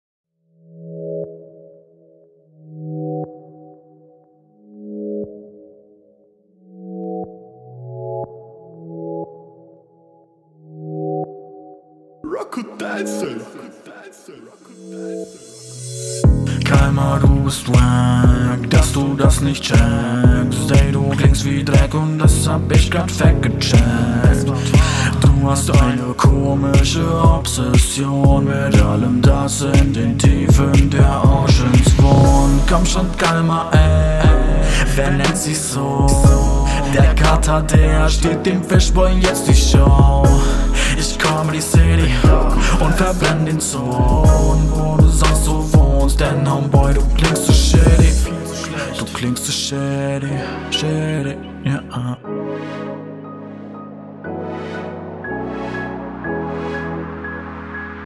klingt für mich so als hättest du beim pitchcorrector den falschen key eingestellt. hörgenuss dadurch …